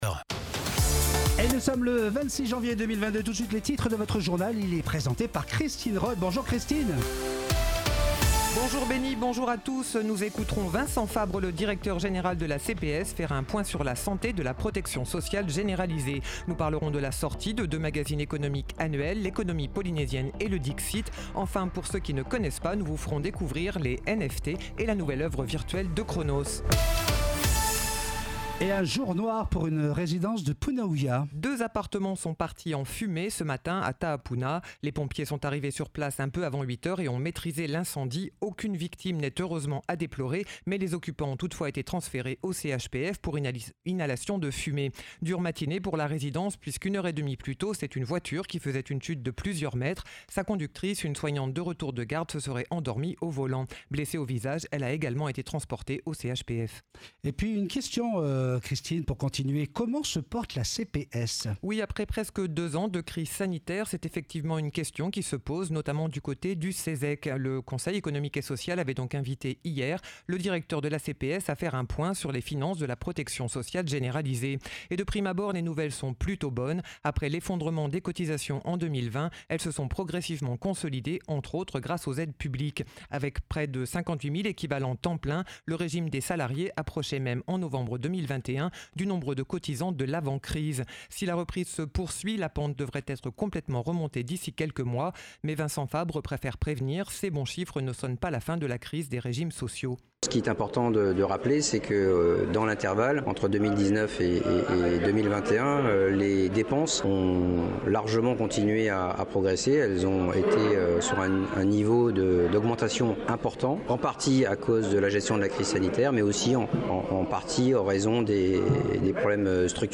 Journal de 12h, le 26/01/22